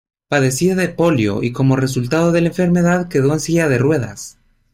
Pronounced as (IPA)
/ˈpoljo/